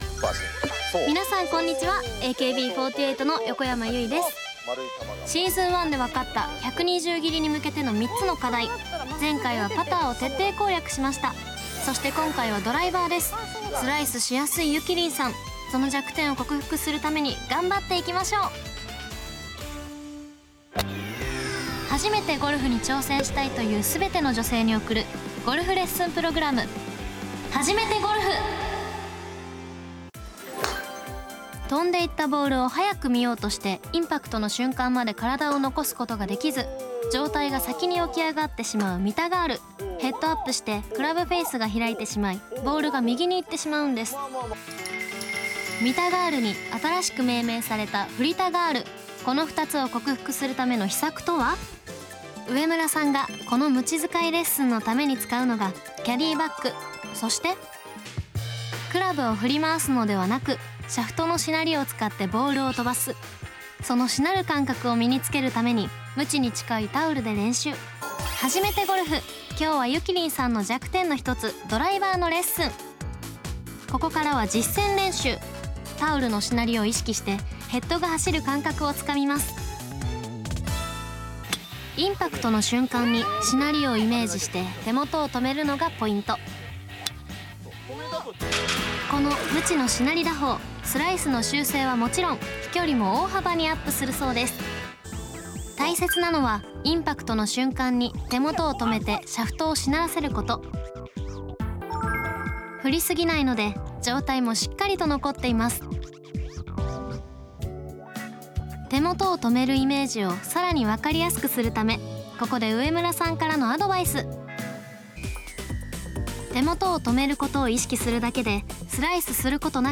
171003 골프방송 시즌2 ep02 - 유이 나레이션 CUT
# 유이 목소리만